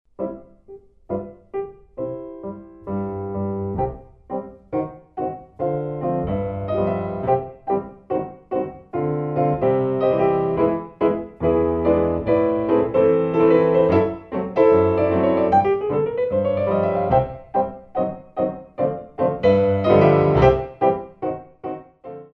Relevés à la barre